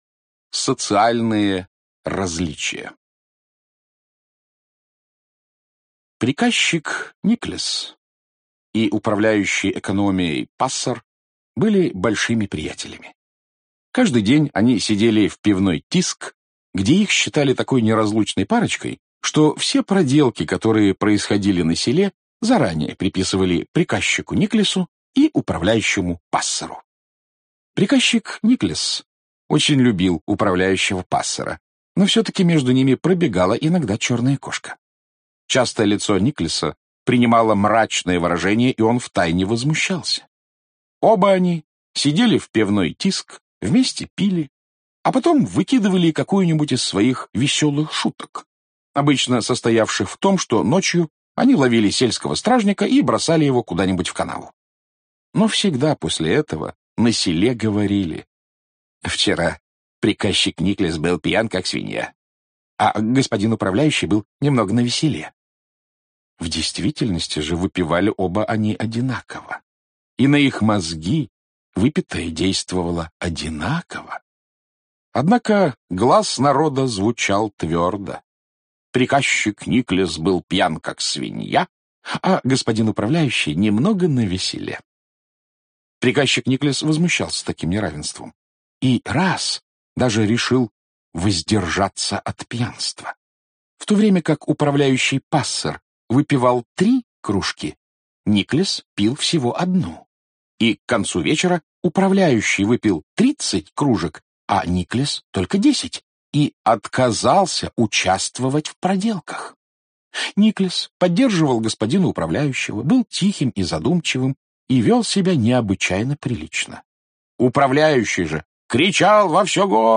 Аудиокнига Юмористические рассказы | Библиотека аудиокниг